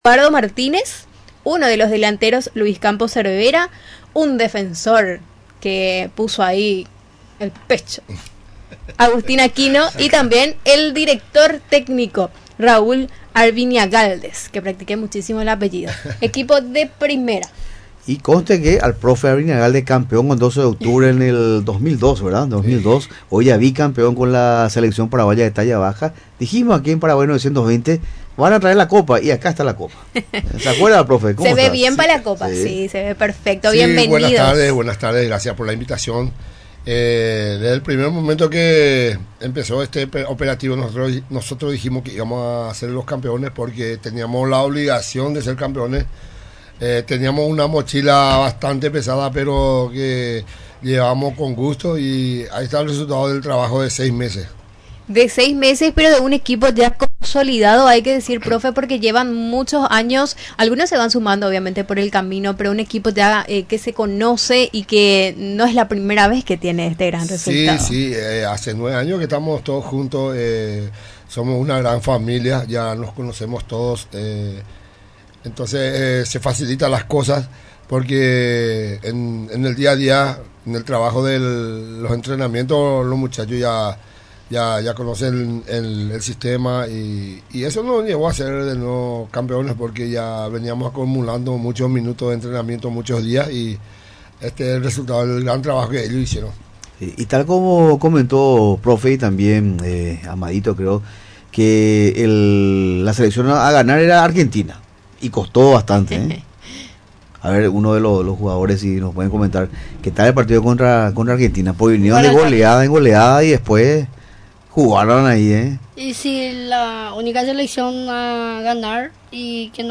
Los Bicampeones de la Copa América de Talla Baja celebran el título en Radio Nacional del Paraguay | RADIO NACIONAL